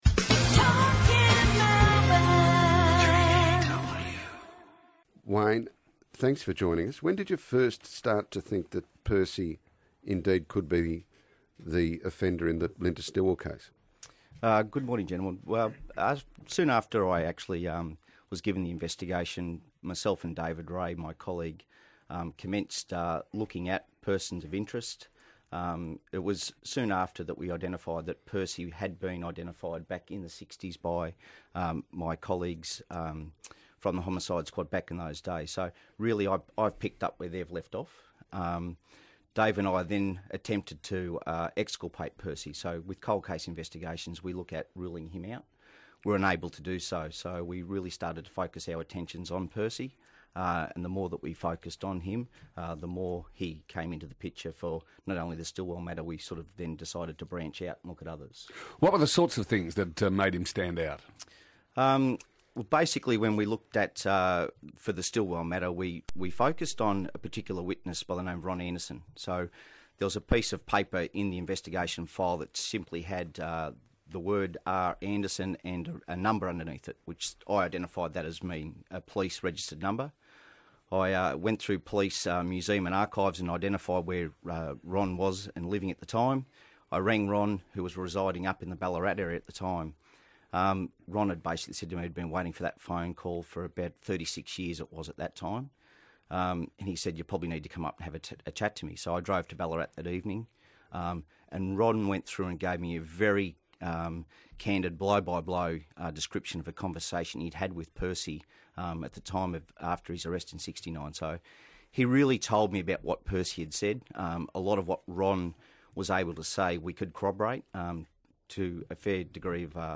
speaks to 3AW Breakfast